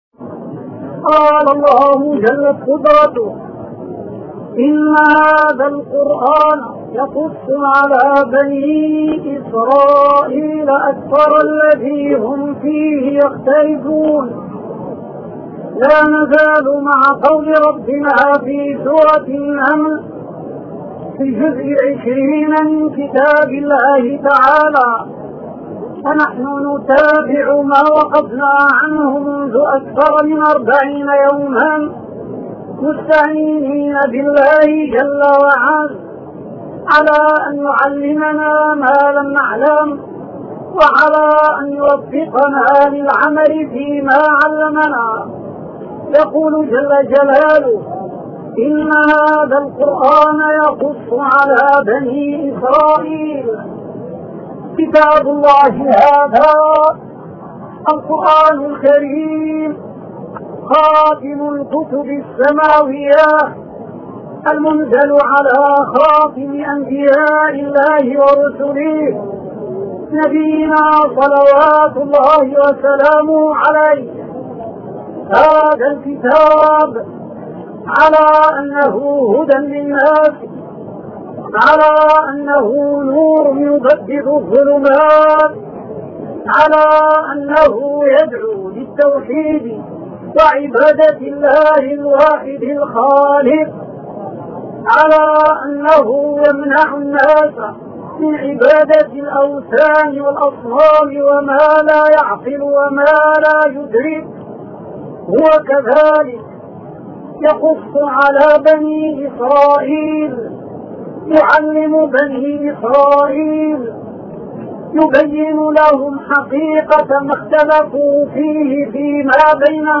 أرشيف الإسلام - أرشيف صوتي لدروس وخطب ومحاضرات